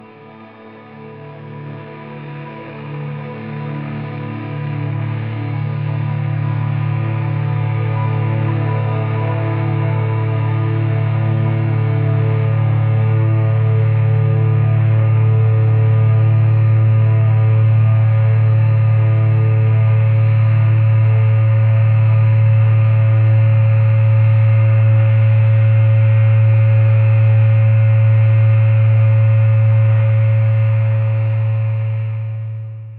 atmospheric | dreamy